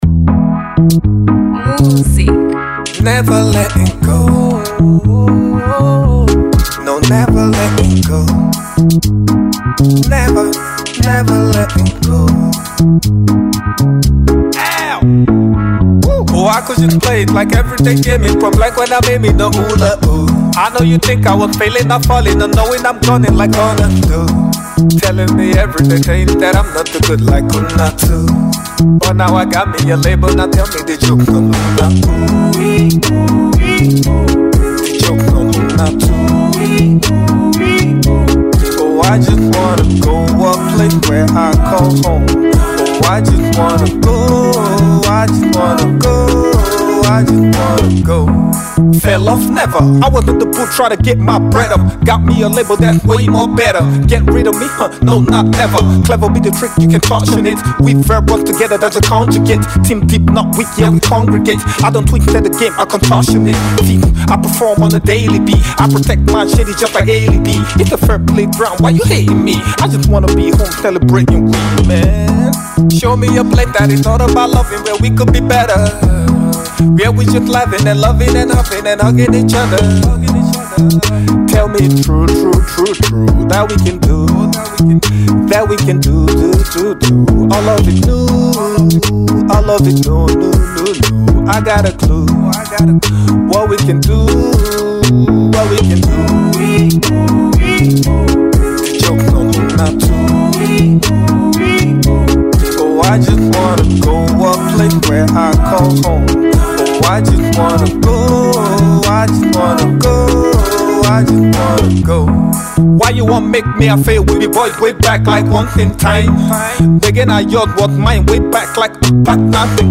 a talented Nigerian rapper based in the UK